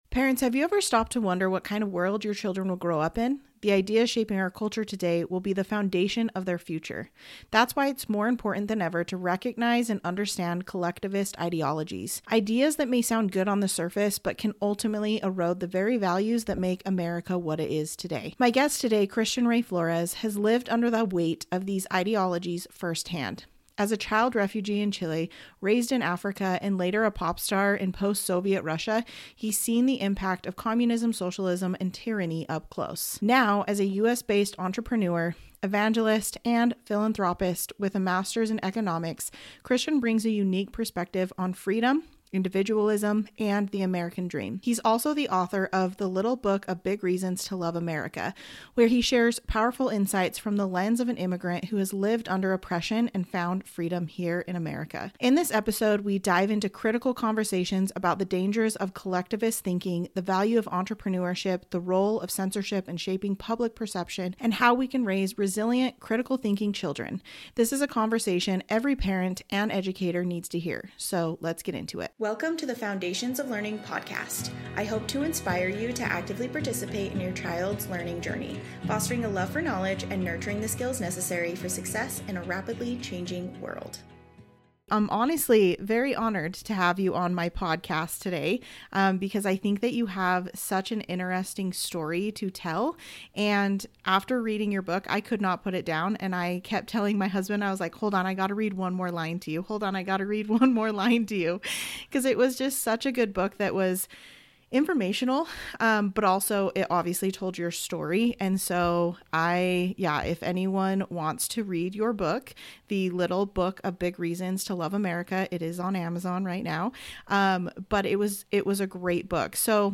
This is an essential conversation for anyone concerned about the future of education, free speech, and the values that define America.